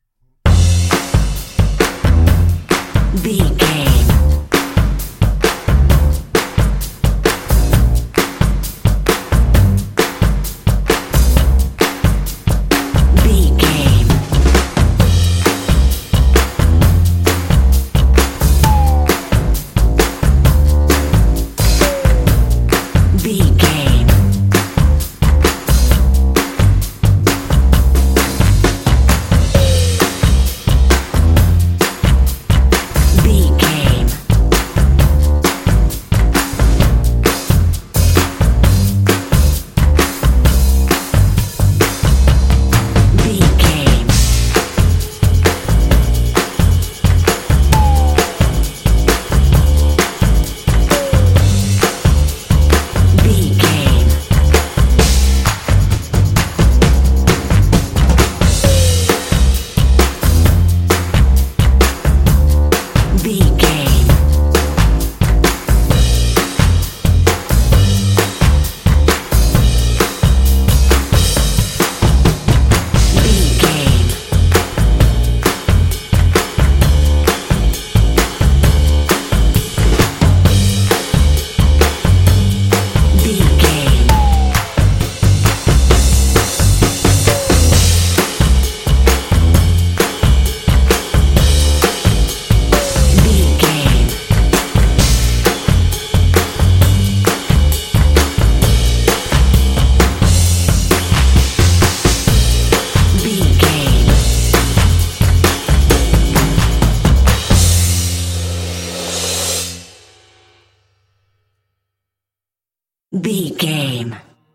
Uplifting
Ionian/Major
happy
bouncy
groovy
drums
brass
electric guitar
bass guitar
strings
rock and roll